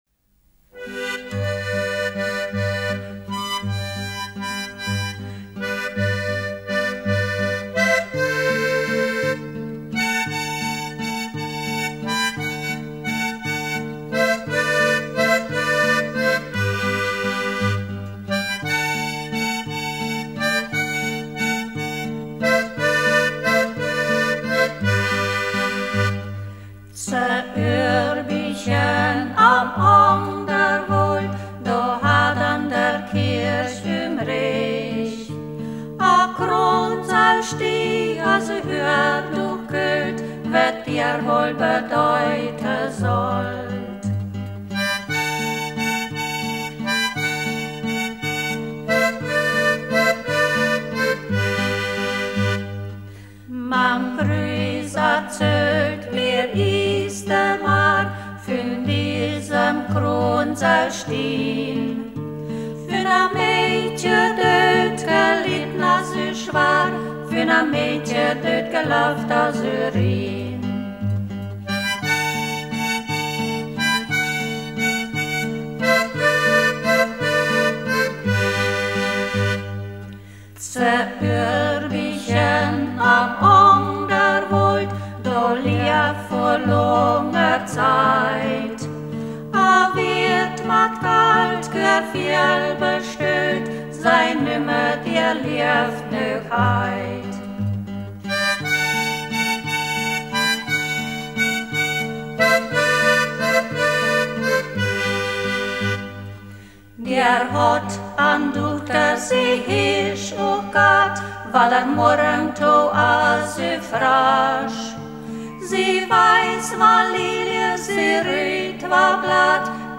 Ortsmundart: Niedereidisch